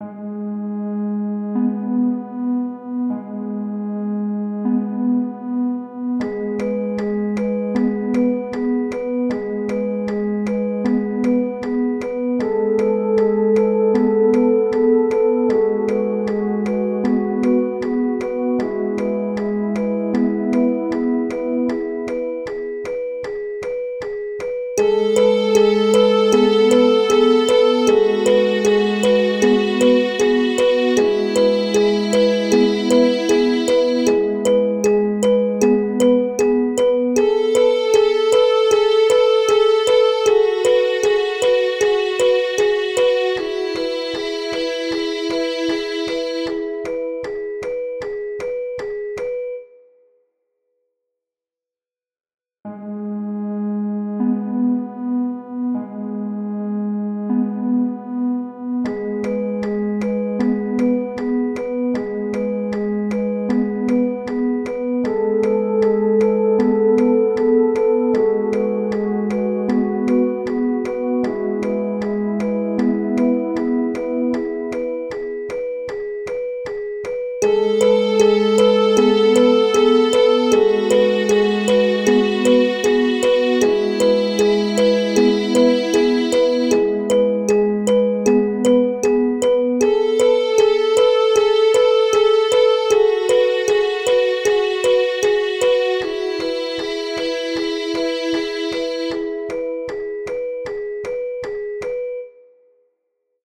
Music / Game Music
ambient melodical